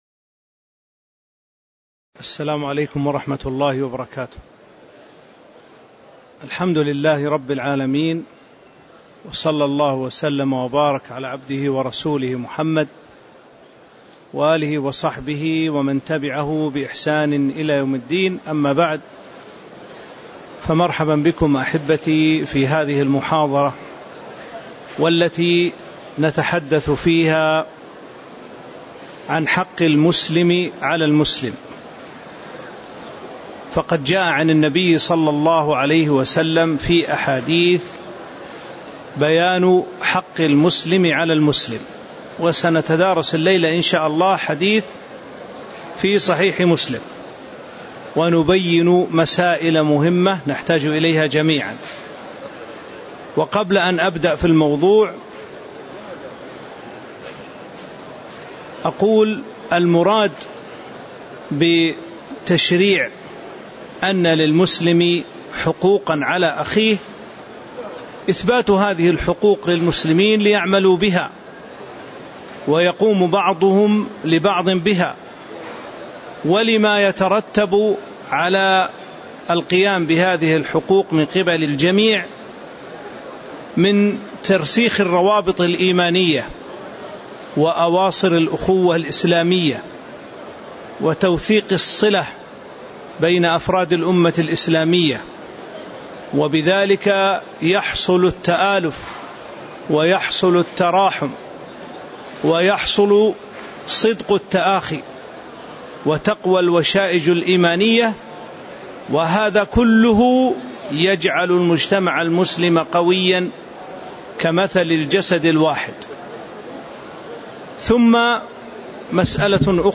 تاريخ النشر ٢٧ ربيع الأول ١٤٤٥ هـ المكان: المسجد النبوي الشيخ